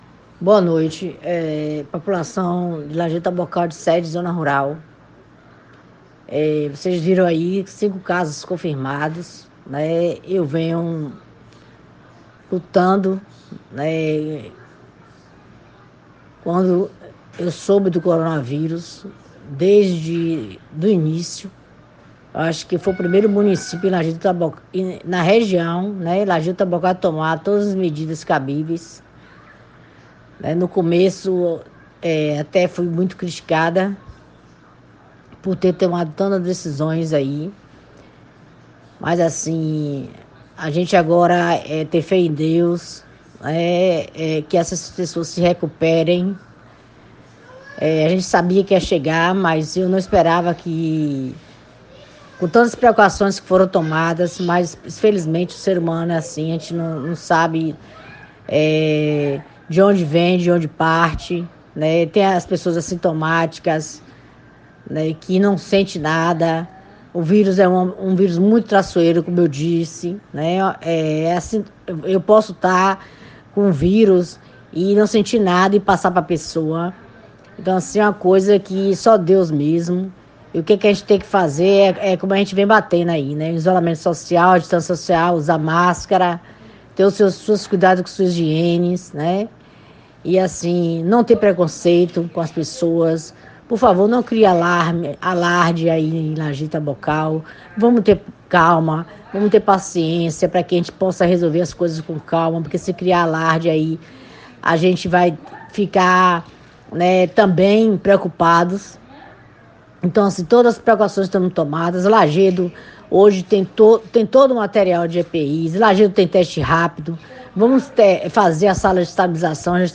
A Prefeita do município anunciou que irá tomar novas medidas austeras diante os números apresentados e fez um apelo aos Lajedenses para que ajudem ficando em casa.